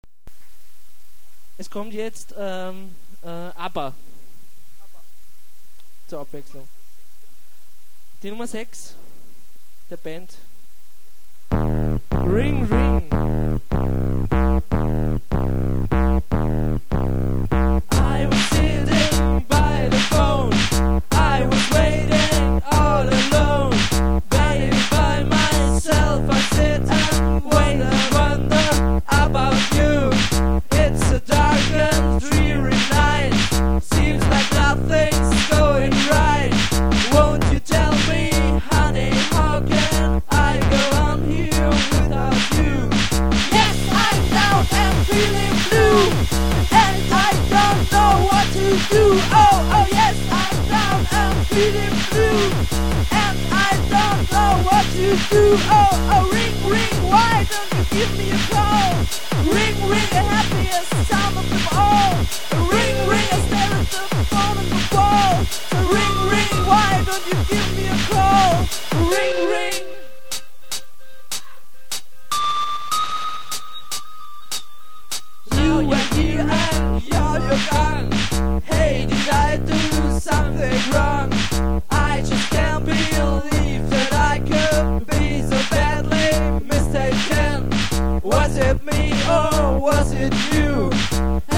[ mp3 ] Very bad live rec.